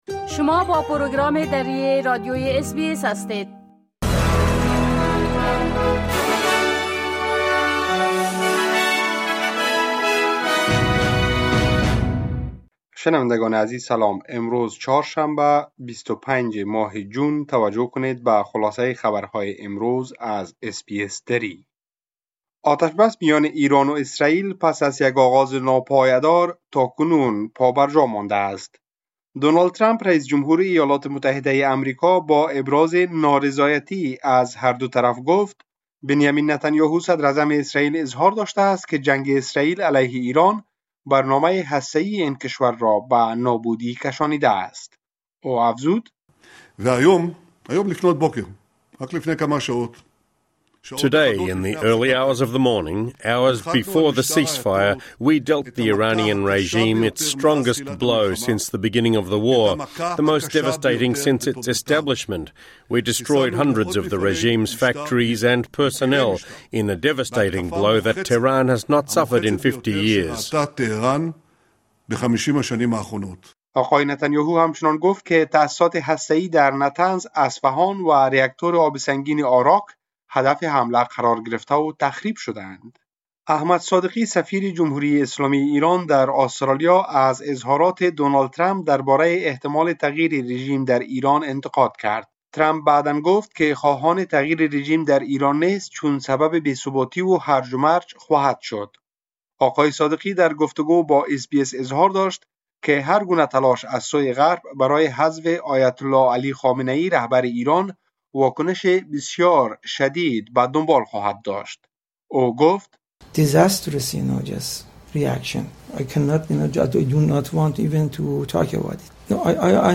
خلاصه مهمترين خبرهای روز از بخش درى راديوى اس‌بى‌اس | ۲۵ جون